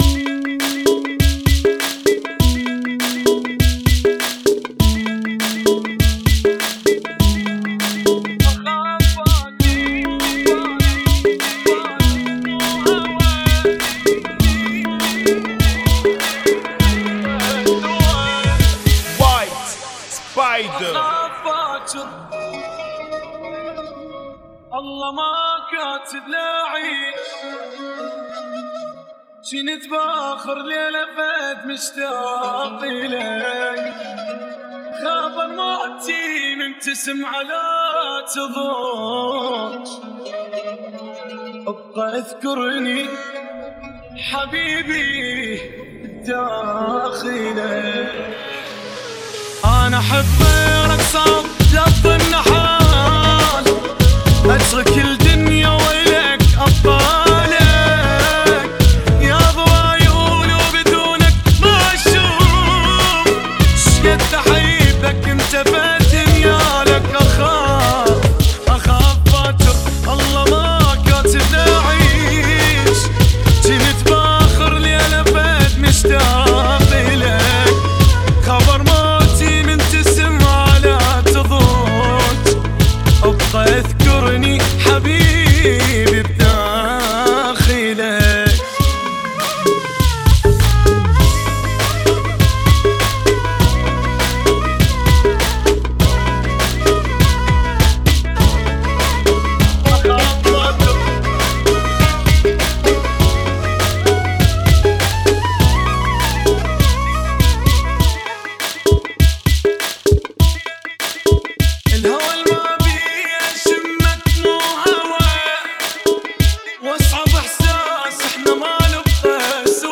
Funky [ 100 Bpm